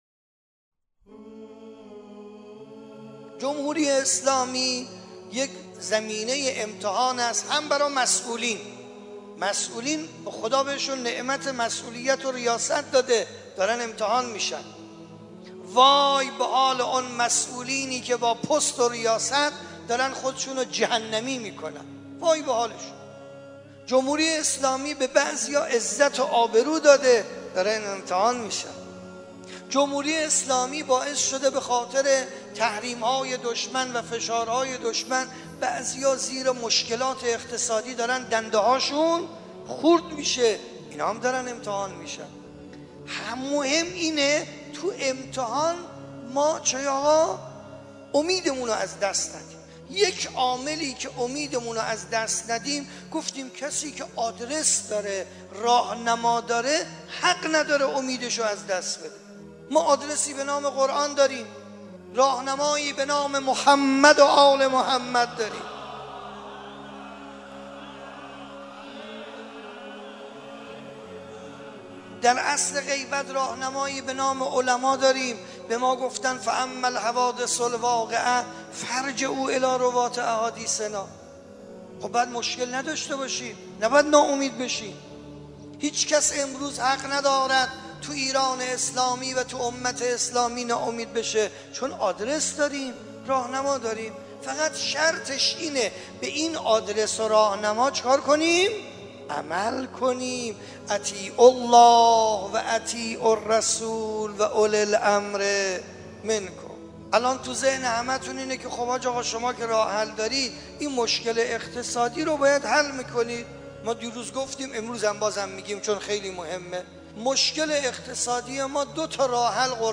حجت‌الاسلام ماندگاری در یکی از سخنرانی‌های خود به راه حل قرآن برای مشکلات اقتصادی اشاره می‌کند که در ادامه تقدیم مخاطبان می‌شود.